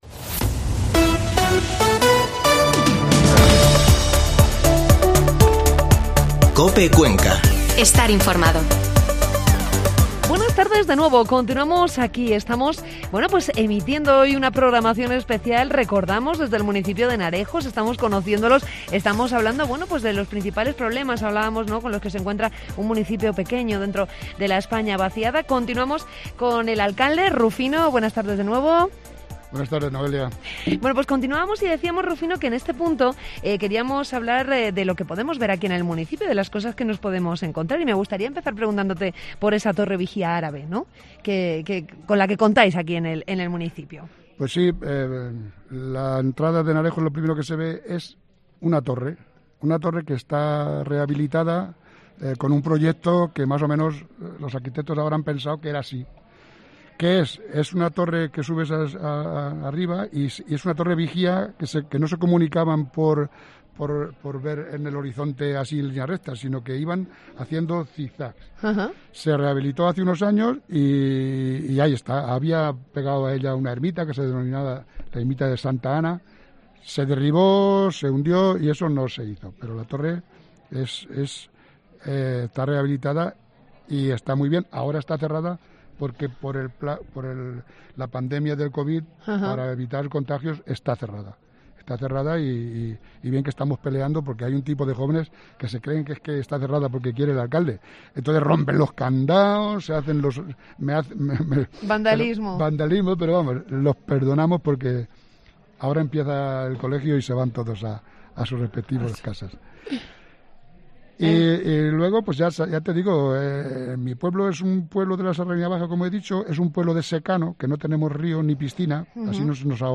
COPE Cuenca se ha trasladado hasta la localidad conquense de Henarejos para conocer las particularidades de este municipio situado en la Serranía Baja de Cuenca. Desde la plaza Mayor de la localidad, se ha emitido un programa especial en el que el alcalde, Rufino Sánchez Palomares, ha destacado los principales problemas con los que se encuentra este municipio de algo más de 140 habitantes.